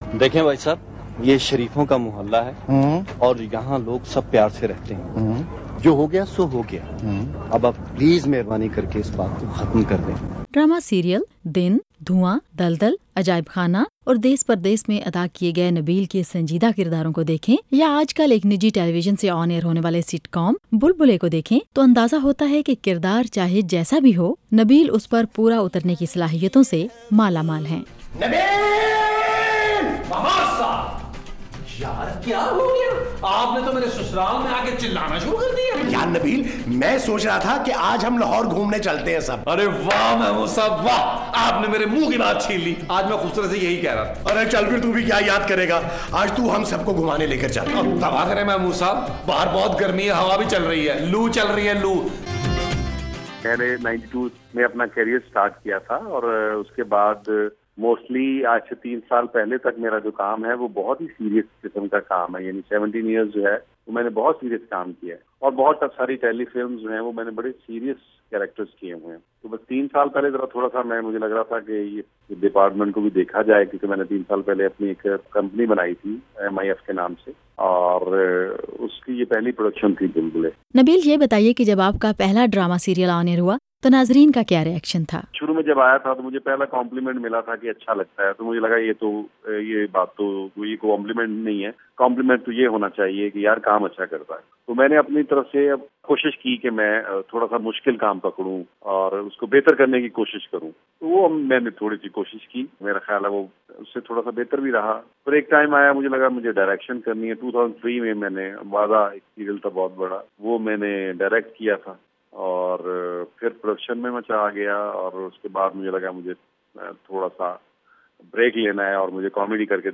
نبیل ظفر کی وائس آف امریکہ سے گفتگو